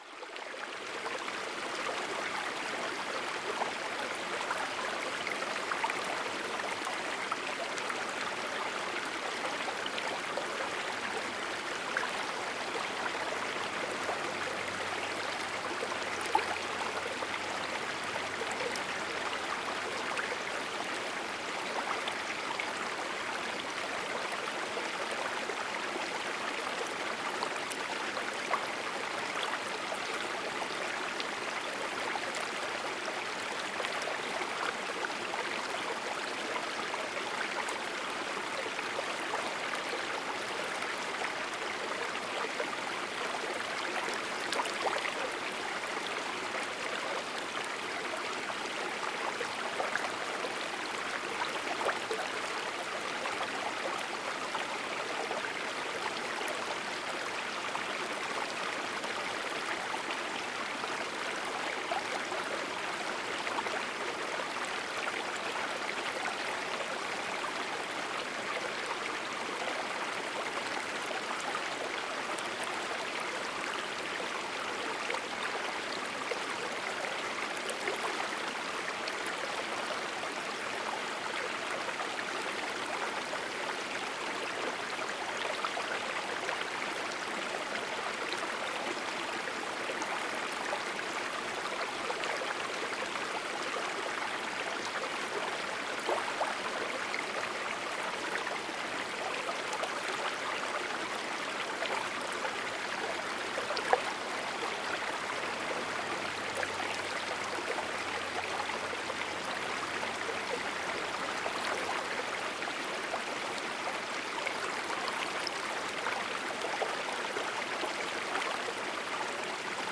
SEELEN-HEILUNG: Gebirgsfluss-Therapie mit sanftem Strömen